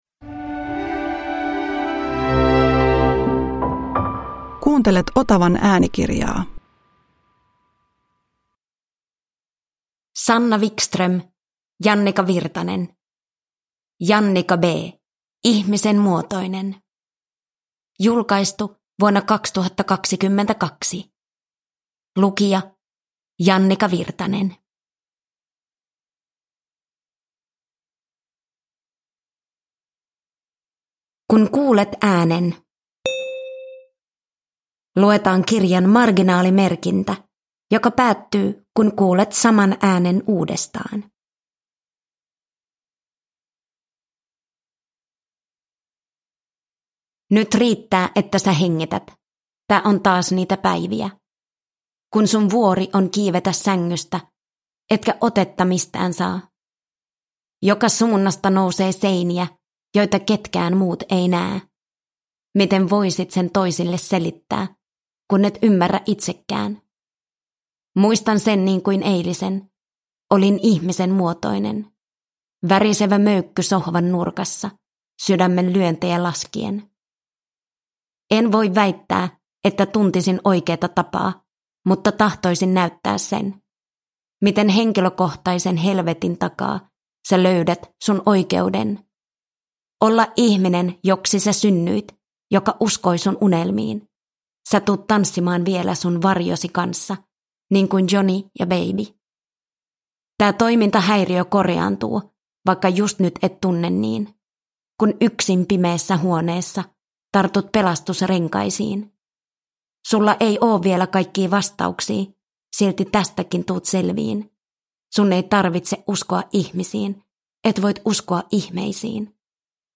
Jannika B - Ihmisen muotoinen – Ljudbok – Laddas ner
Uppläsare: Jannika Wirtanen